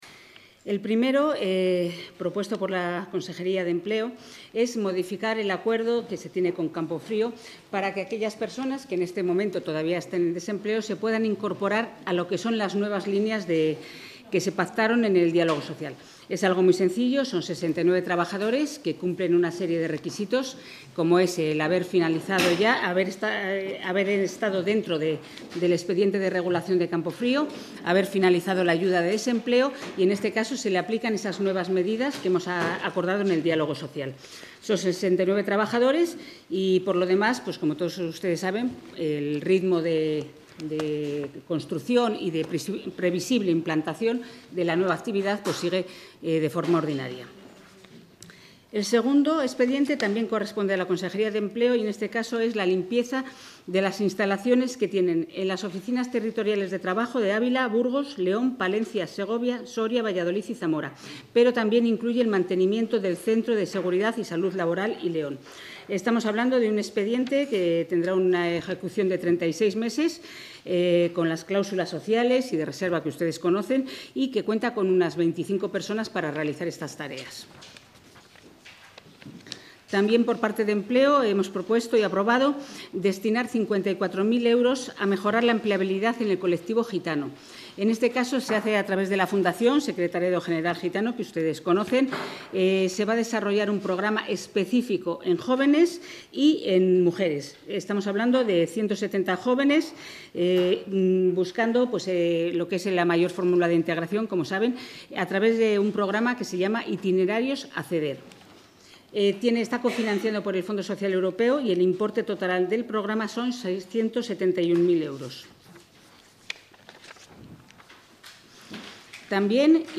Audio de la ruda de prensa posterior al Consejo de Gobierno.